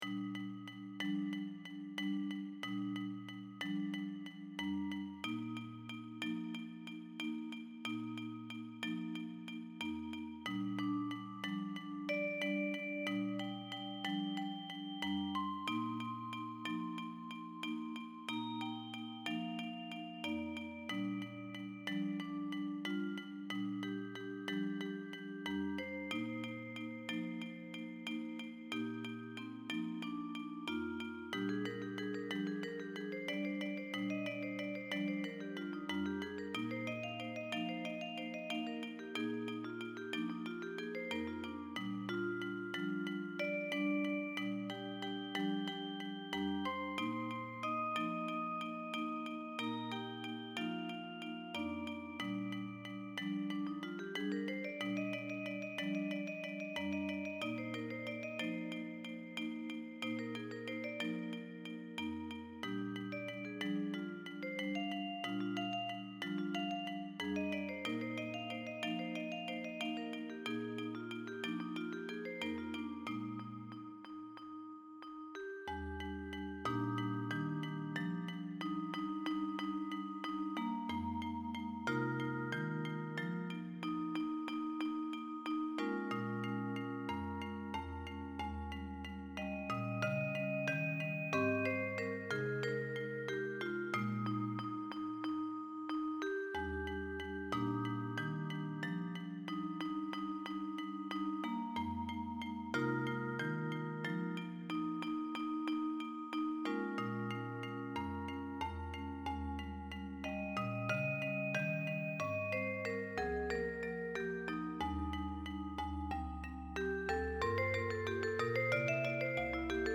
Instrumentation: percussions
classical